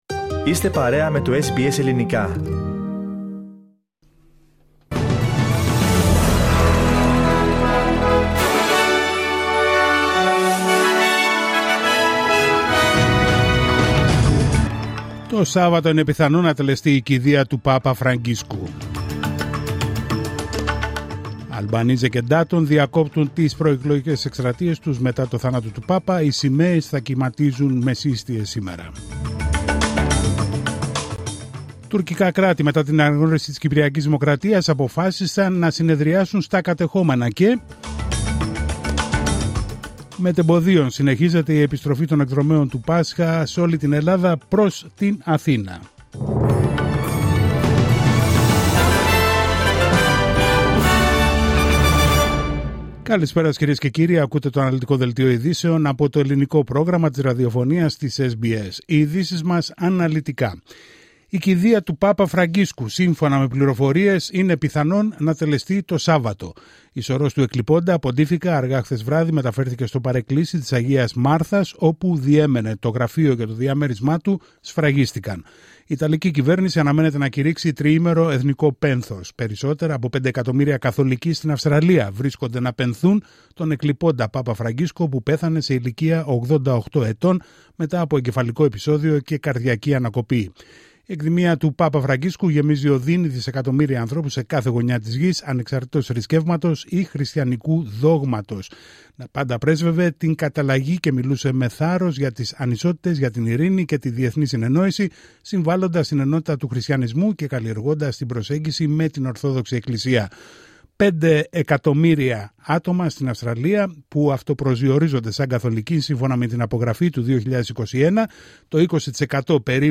Δελτίο ειδήσεων Τρίτη 22 Απρίλιου 2025